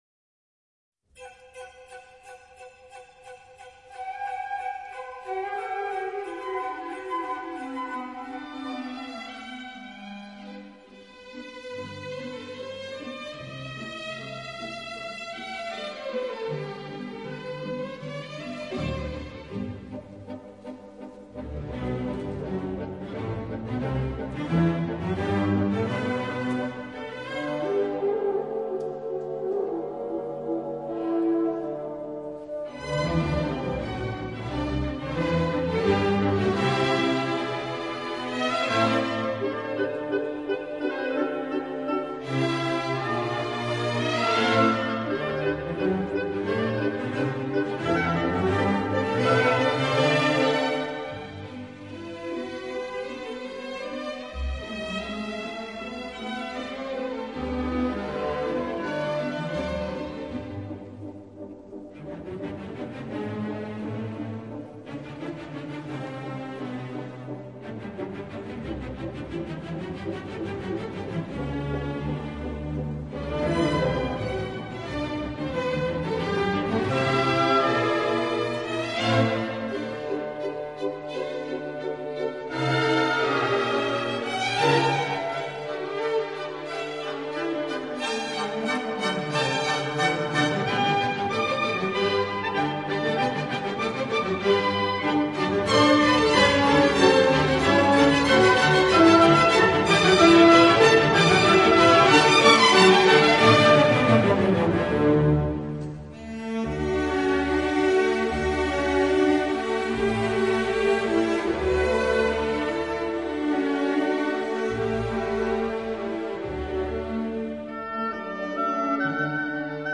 女 高 音
有一段以铃声与长笛构成短短的Ｂ 小调序奏。
先以大提琴呈示优美的第一主题，小提 琴以对位旋律加入后，由双簧管展开与第一主题成对比的第二主题，小提琴表现悲叹的歌调。